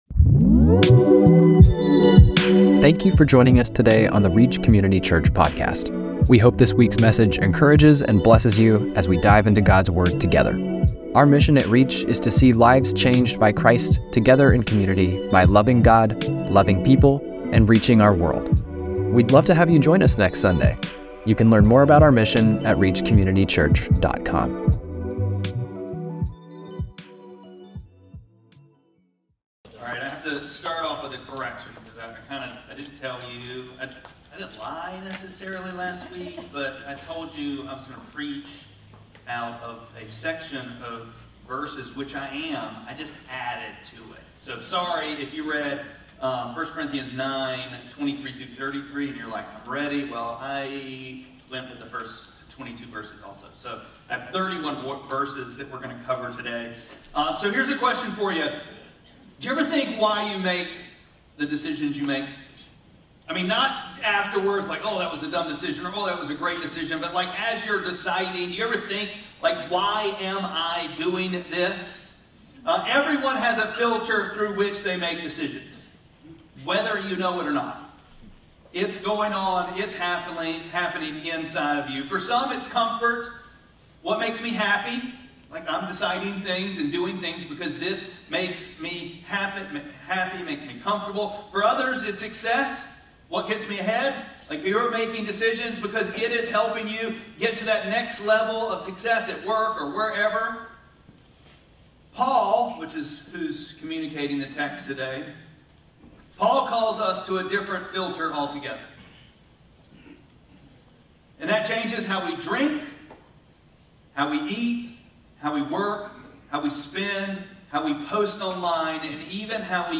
8-31-25-Sermon.mp3